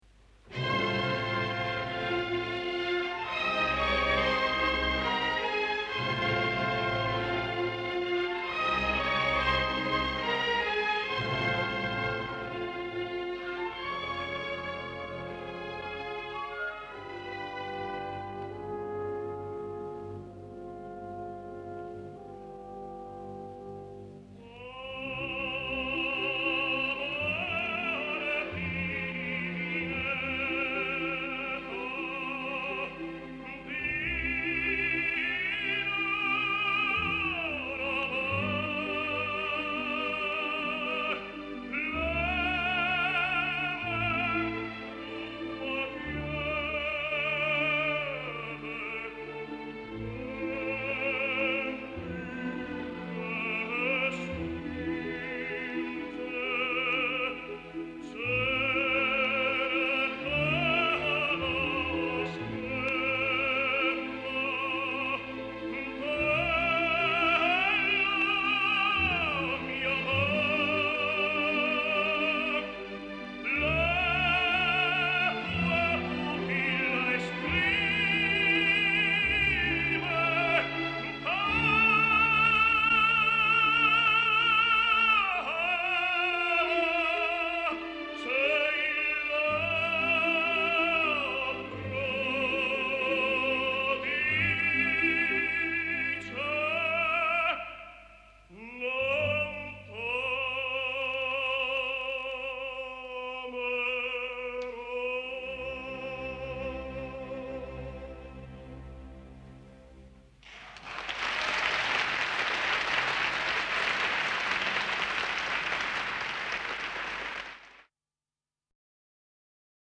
Несколько записей итальянского тенора Даниеле Бариони (1930 г.р).